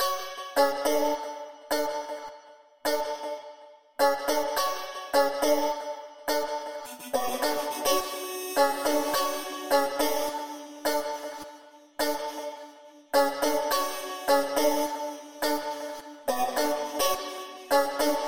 描述：调：Fmin 速度：105bpm 芥末型弹拨引线